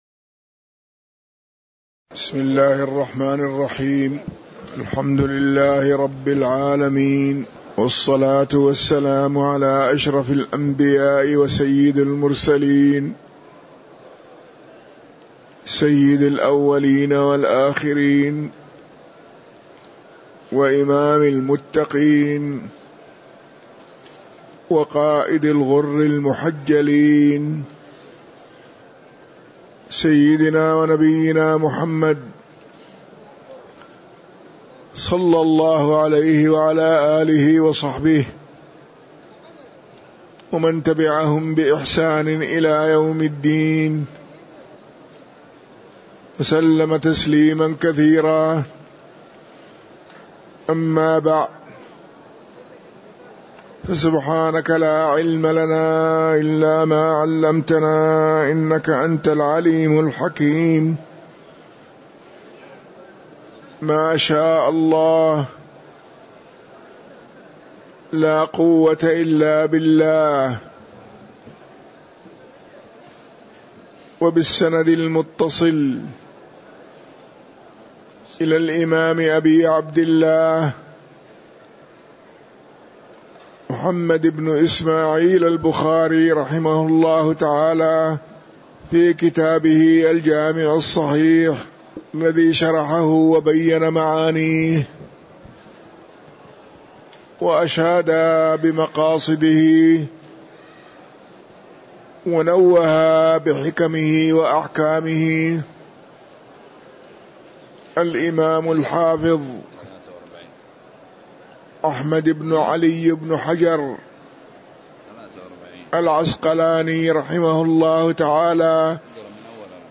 تاريخ النشر ١٢ محرم ١٤٤٤ هـ المكان: المسجد النبوي الشيخ